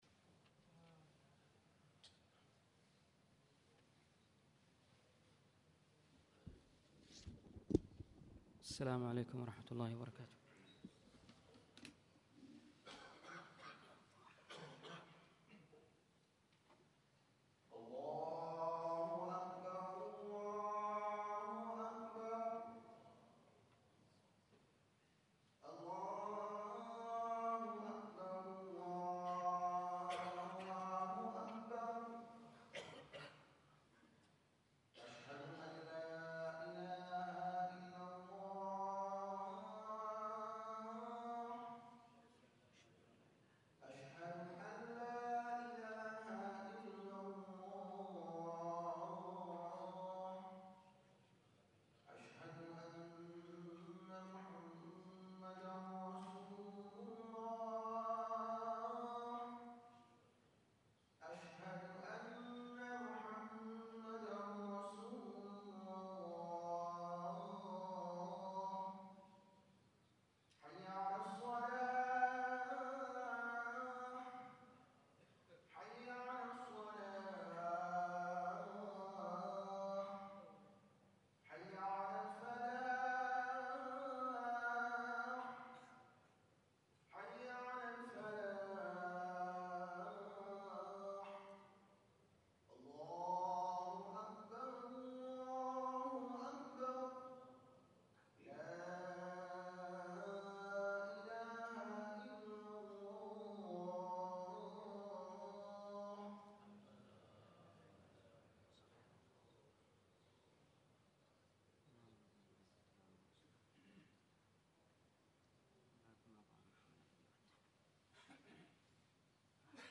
الخطبه
أشرف أفكار الإنسان خطب الجمعة